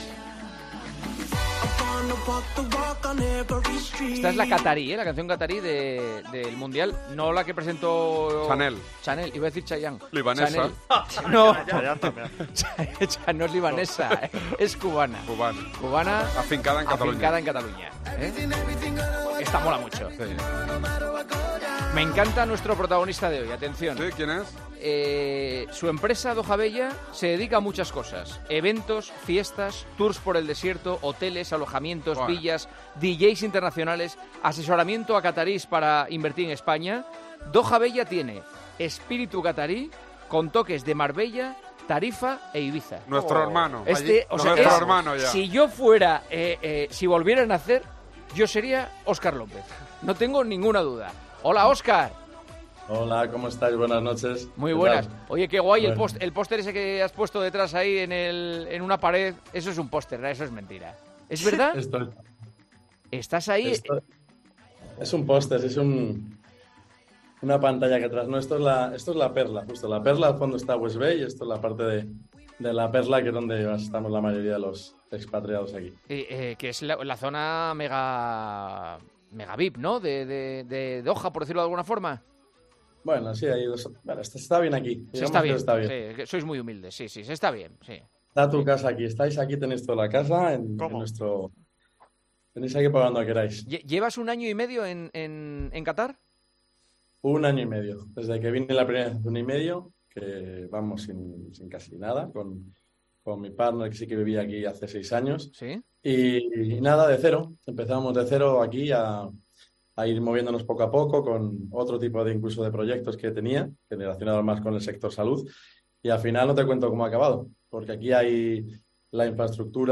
AUDIO: Hablamos con el empresario español en Doha sobre qué cosas no hay que perderse si vas a ver los partidos del Mundial.